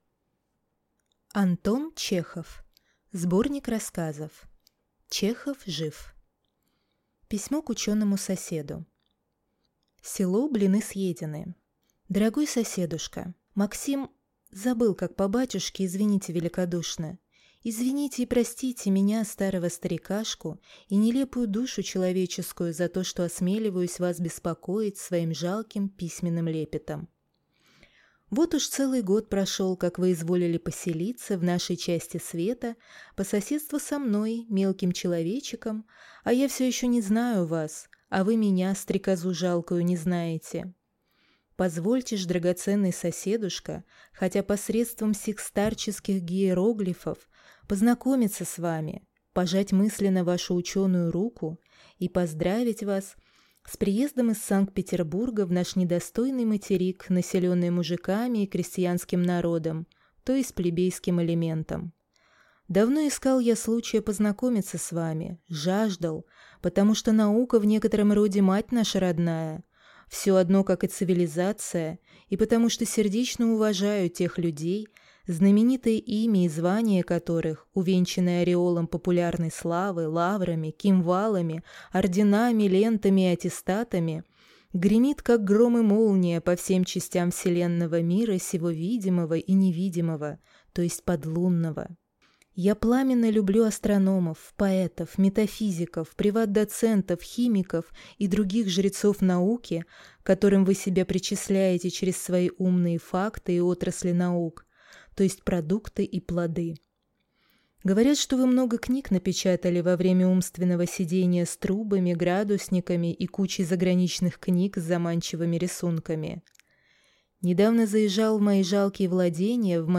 Аудиокнига Чехов жив. Сборник рассказов | Библиотека аудиокниг